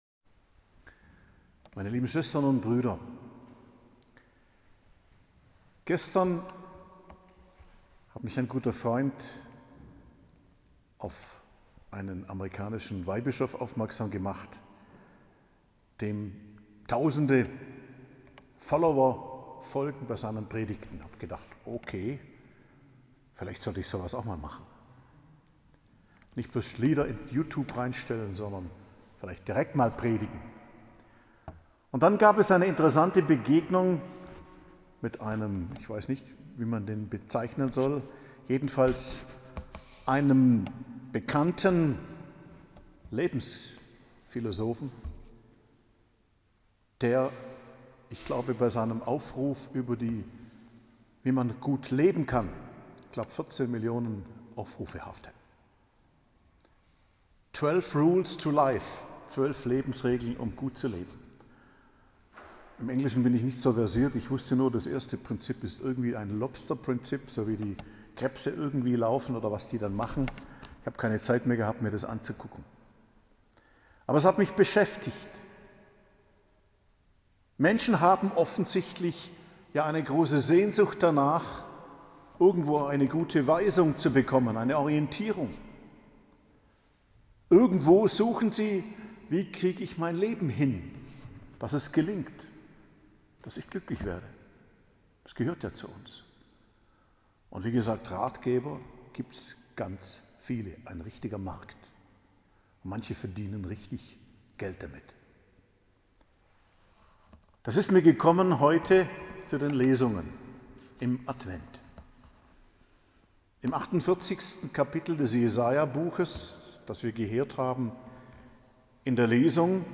Predigt am Freitag der 2. Woche im Advent, 10.12.2021